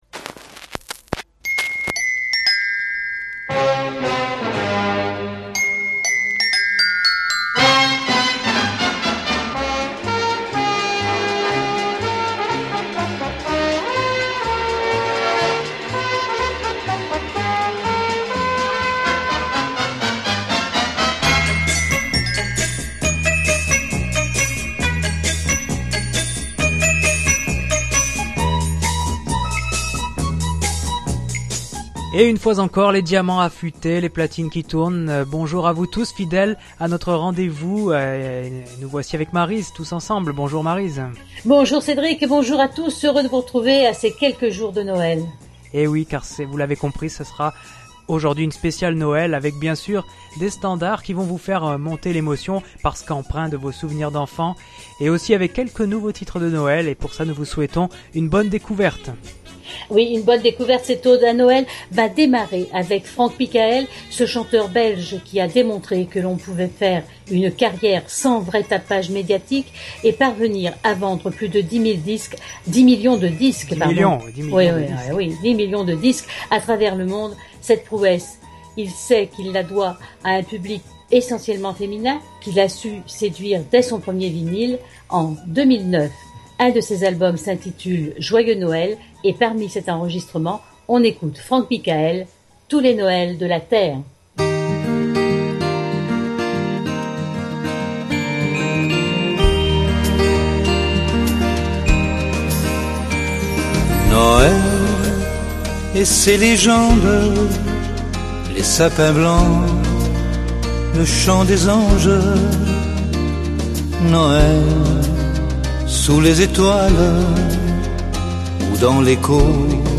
Le Podcast Journal vous propose cette émission musicale dédiée aux années vinyles